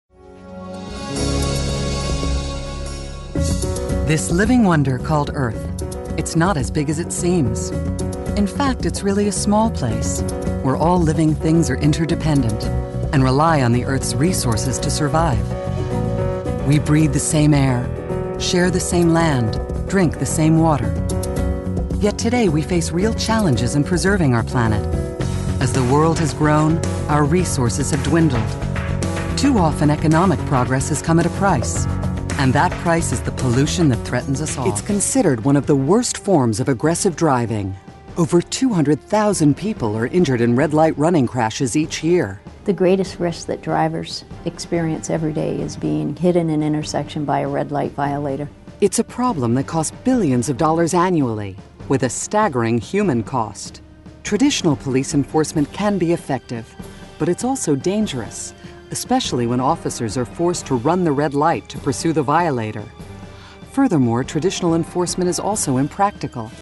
DEMOS
Narration
female, Democrat, audition copy, west coast, warm, friendly, young, millennial, positive, political